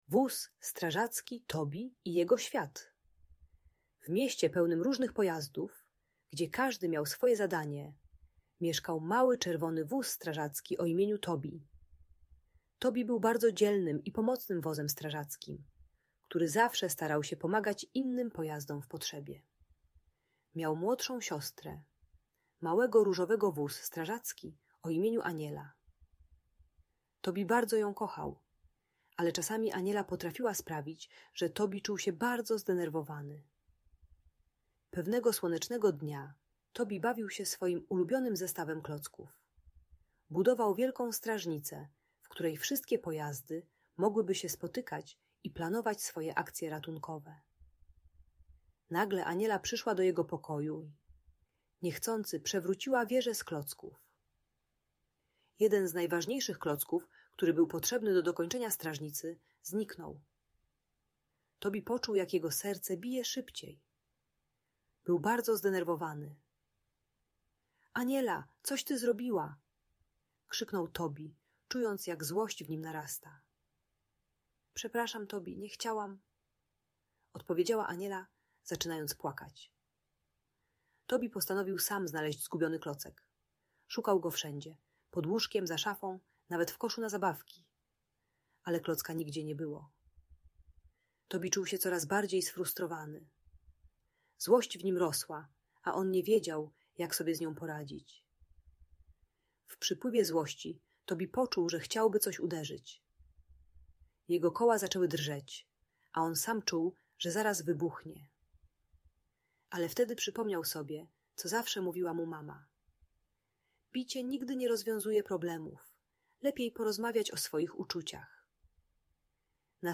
Wóz Strażacki Tobi - Bunt i wybuchy złości | Audiobajka